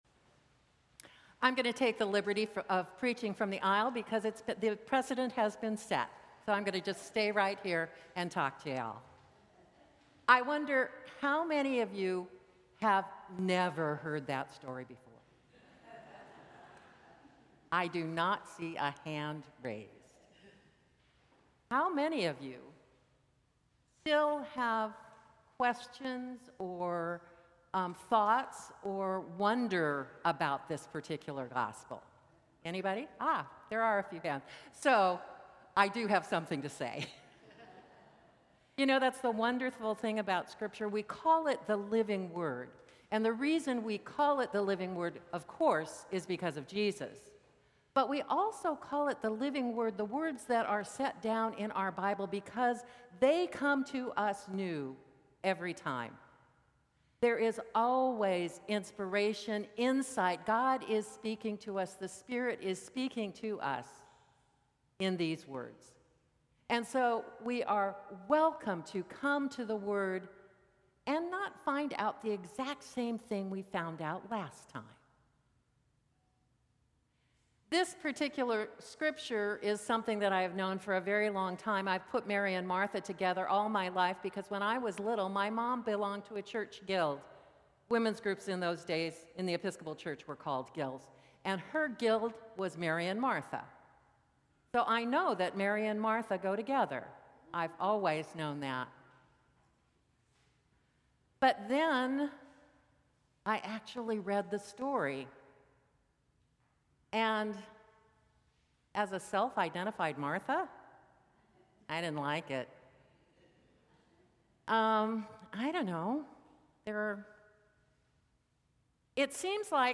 Sermons from St. Cross Episcopal Church Sixth Sunday after Pentecost Jul 20 2025 | 00:11:11 1x 00:00 / 00:11:11 Subscribe Share Apple Podcasts Spotify Overcast RSS Feed Share Link Embed